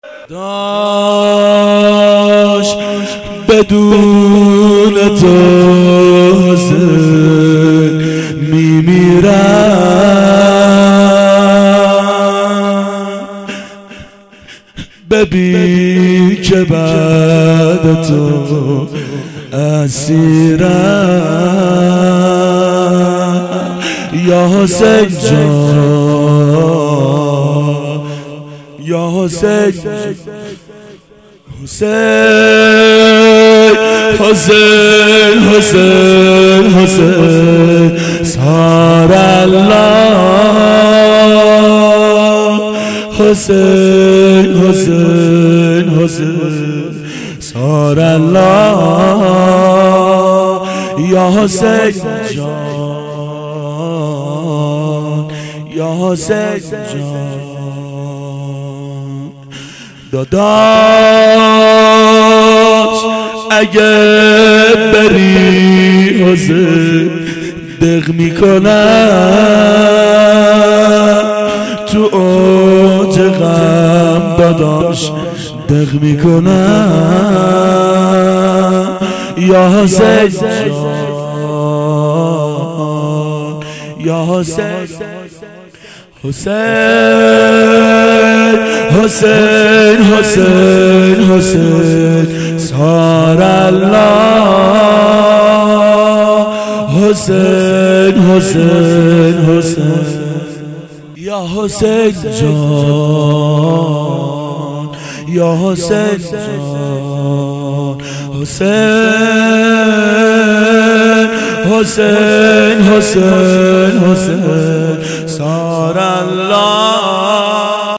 شب عاشورا 1390 هیئت عاشقان اباالفضل علیه السلام